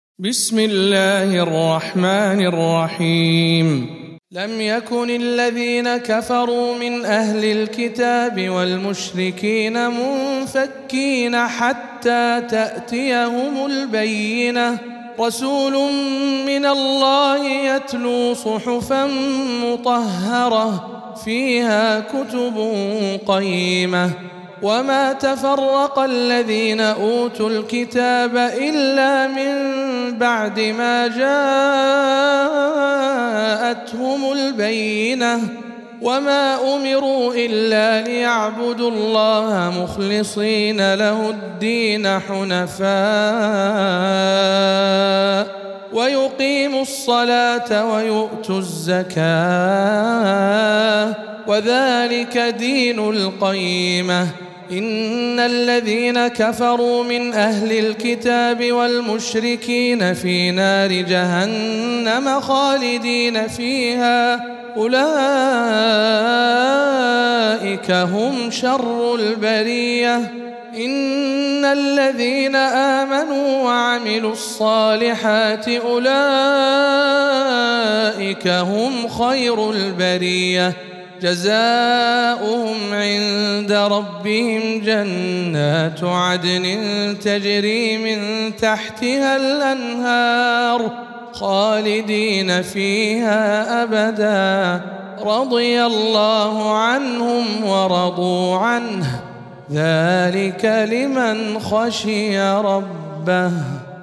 سورة البينة - رواية رويس عن يعقوب